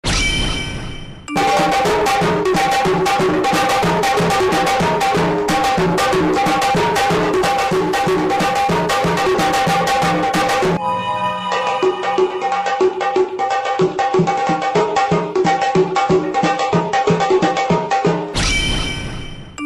Roblox Arabic Horror meme sound effect
Roblox-Arabic-Horror-meme-sound-effect.mp3